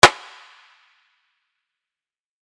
menuconfirm.mp3